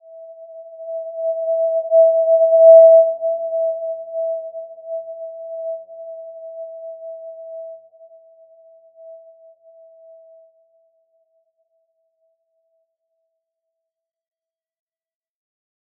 Simple-Glow-E5-mf.wav